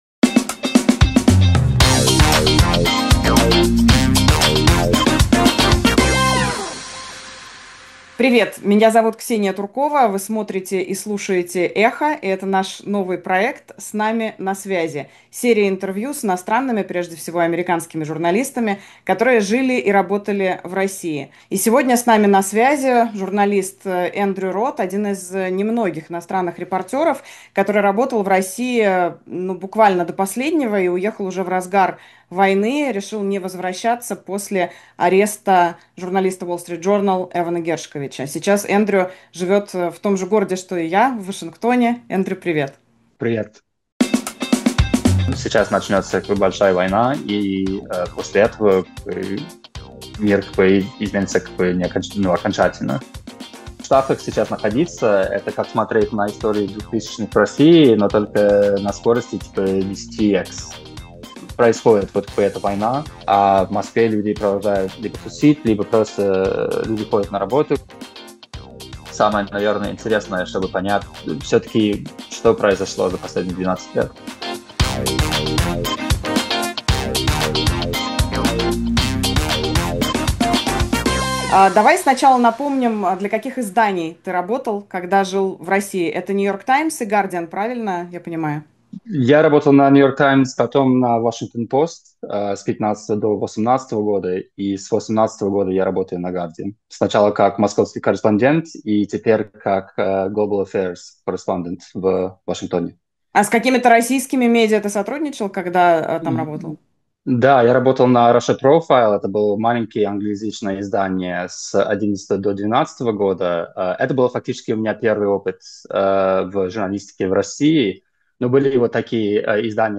Вы смотрите и слушаете «Эхо», и это наш новый проект «С нами на связи» — серия интервью с иностранными, прежде всего американскими журналистами, которые жили и работали в России.